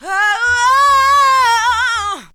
WHOA WHOA.wav